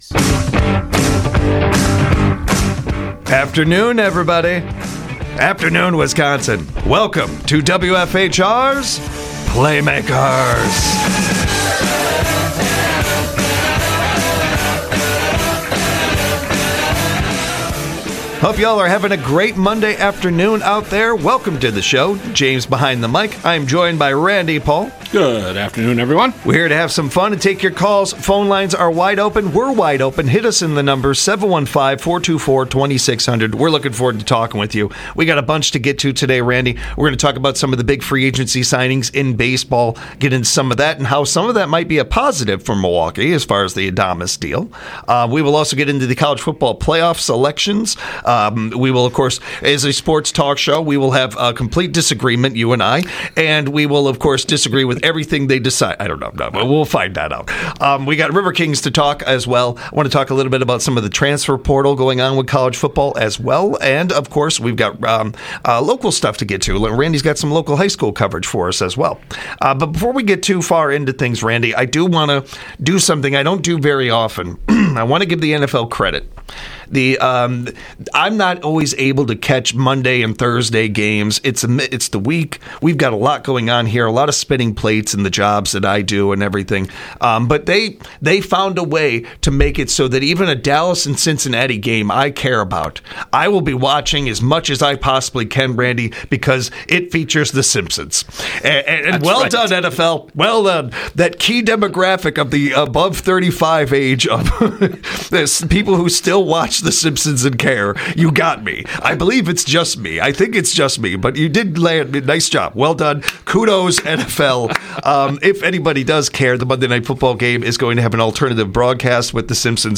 With local sports guests and call-ins from the audience, this show is a highlight every Monday, Wednesday, Friday from 5pm - 6pm on WFHR.